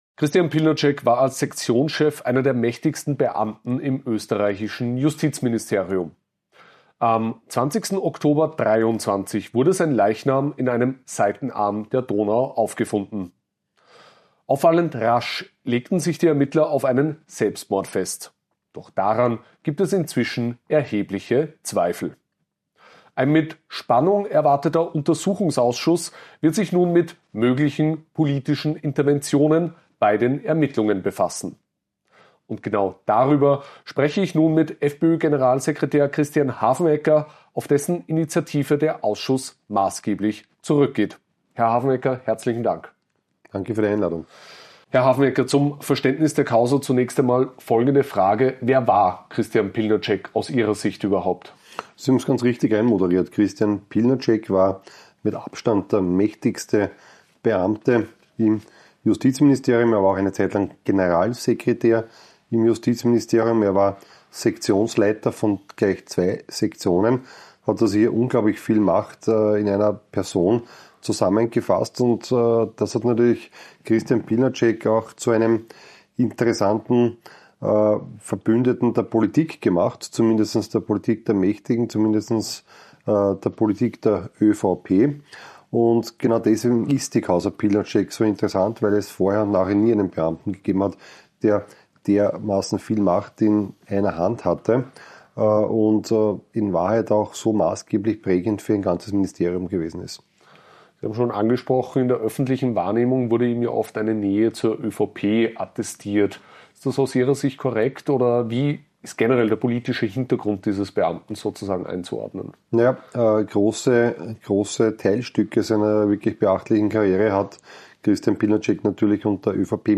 Worum es in dem Fall eigentlich gehe und wieso die ÖVP den Ausschuss bereits jetzt fürchte, erklärt FPÖ-Fraktionschef Christian Hafenecker im Exklusivinterview mit AUF1.